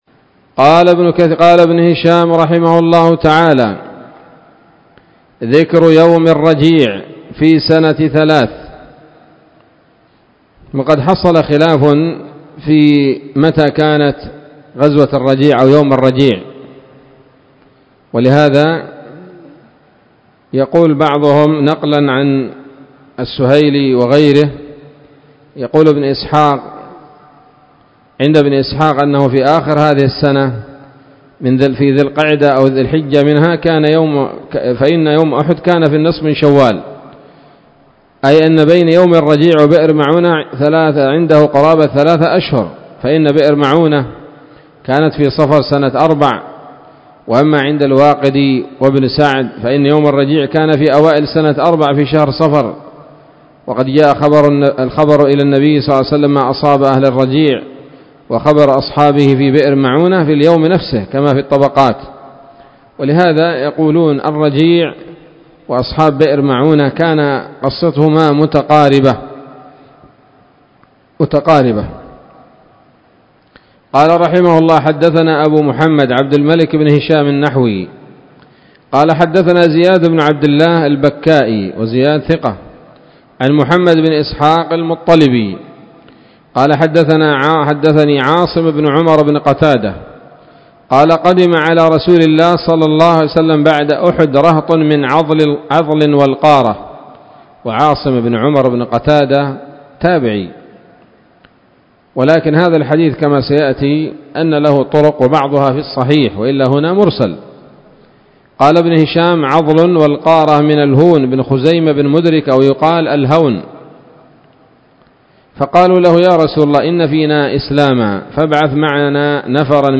الدرس الثالث والثمانون بعد المائة من التعليق على كتاب السيرة النبوية لابن هشام